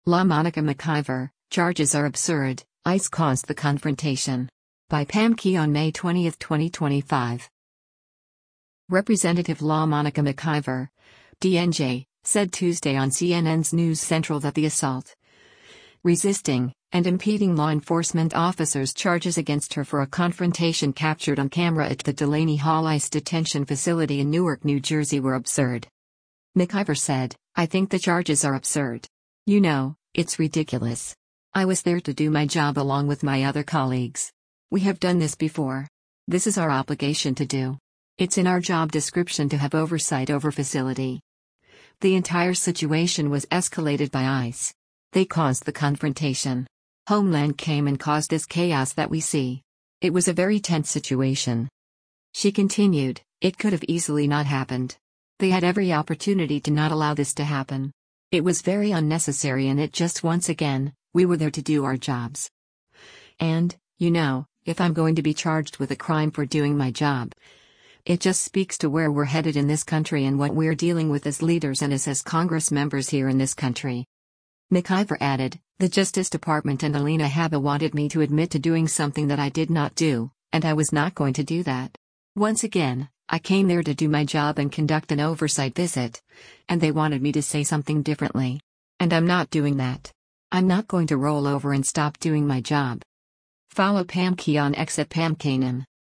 Representative LaMonica McIver (D-NJ) said Tuesday on CNN’s “News Central” that the assault, resisting, and impeding law enforcement officers charges against her for a confrontation captured on camera at the Delaney Hall ICE detention facility in Newark, NJ were “absurd.”